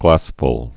(glăsfl)